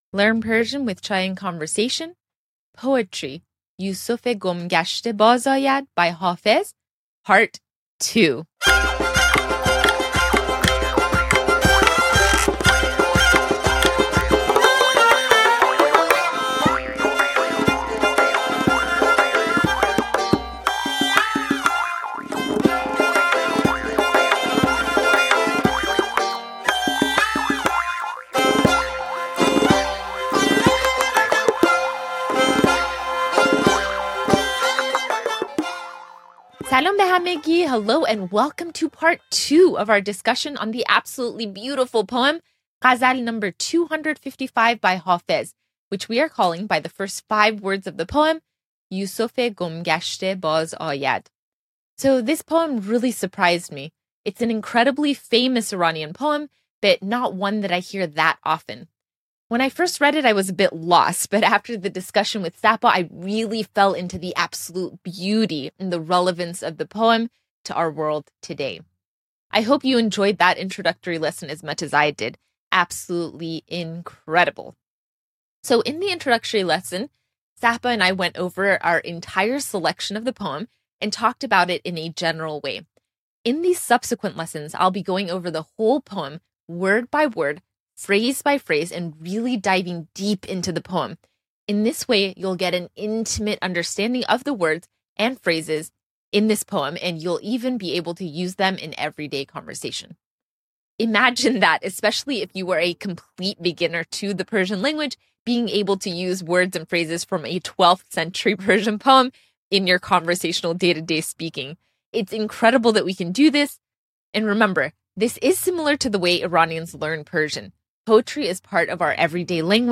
In this lesson, we go over the individual words and phrases in the first four lines of the poem yoosofé gom gashté by Hafez.